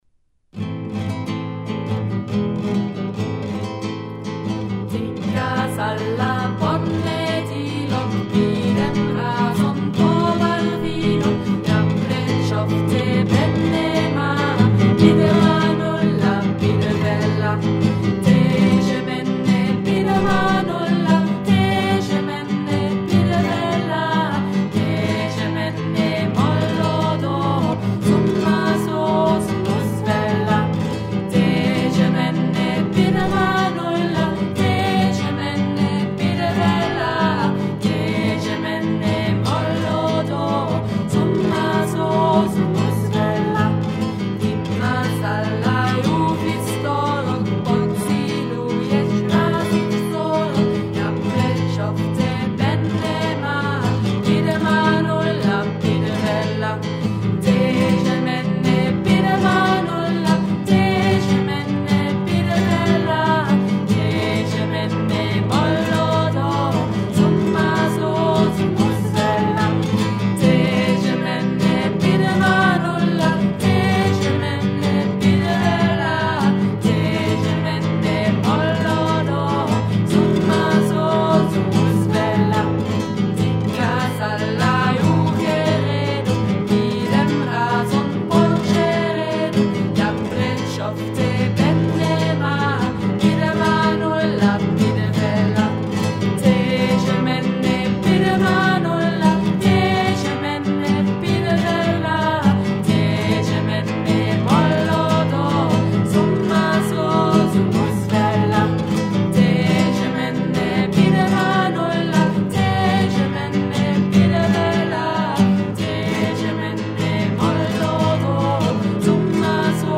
Oekraïens volksliedje